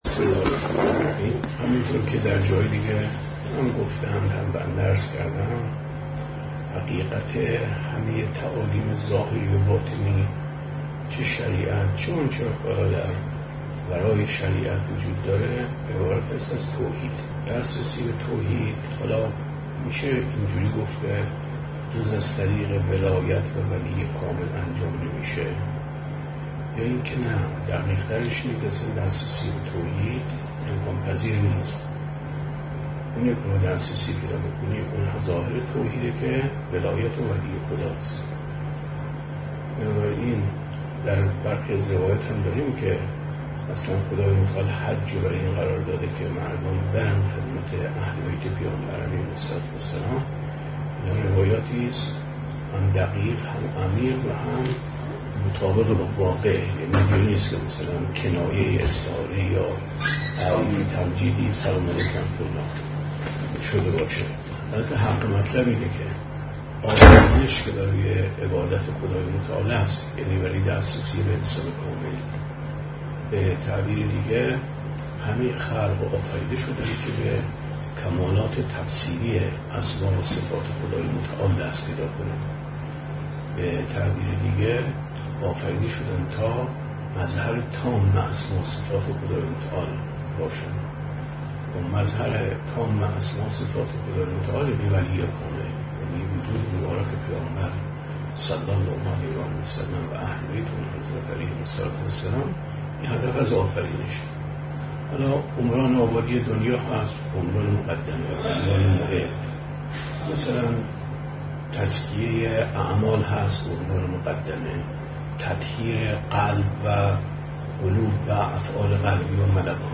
گفتاری